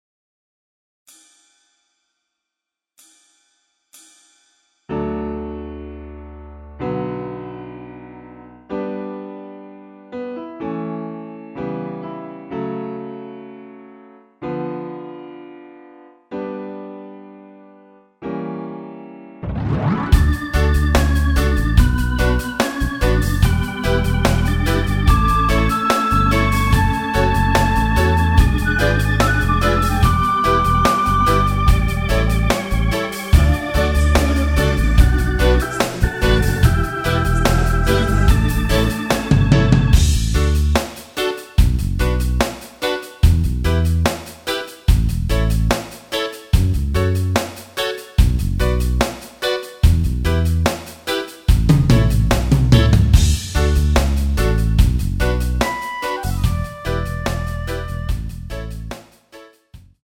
전반, 간주, 엔딩 부분의 (-2)코러스 포함된 MR 입니다.(미리듣기 참조)
앞부분30초, 뒷부분30초씩 편집해서 올려 드리고 있습니다.
중간에 음이 끈어지고 다시 나오는 이유는